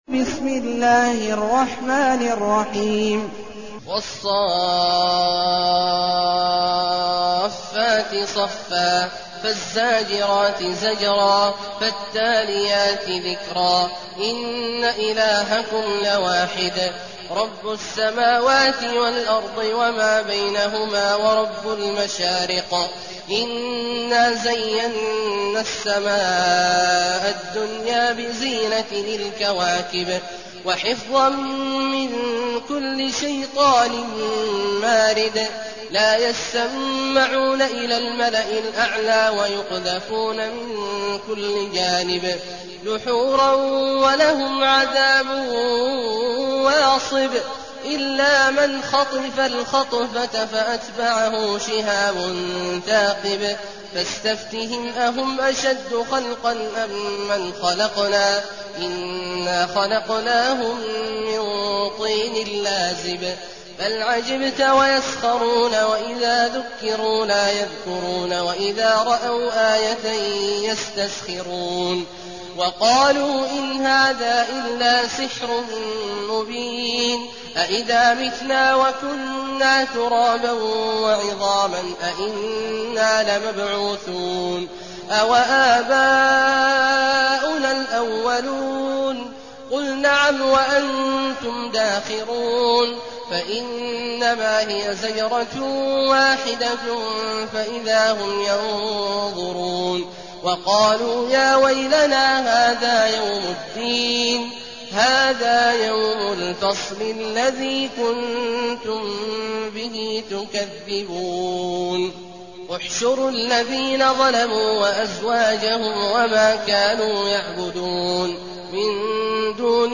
المكان: المسجد النبوي الشيخ: فضيلة الشيخ عبدالله الجهني فضيلة الشيخ عبدالله الجهني الصافات